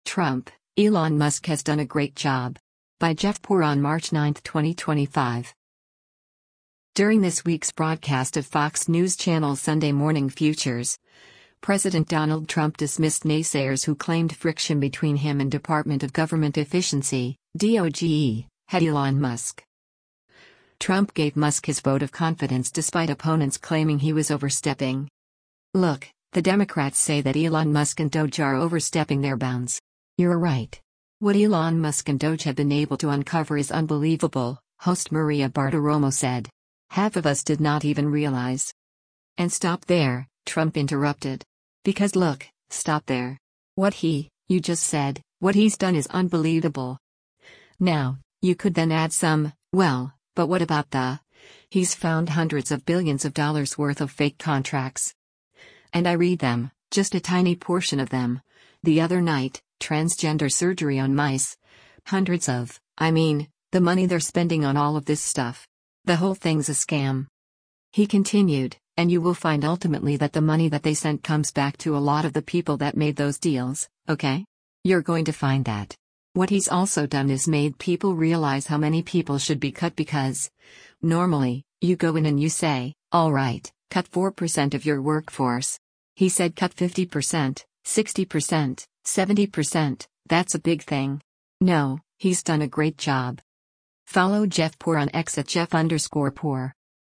During this week’s broadcast of Fox News Channel’s “Sunday Morning Futures,” President Donald Trump dismissed naysayers who claimed friction between him and Department of Government Efficiency (DOGE) head Elon Musk.